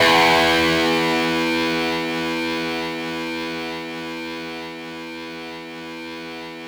NoteE2.wav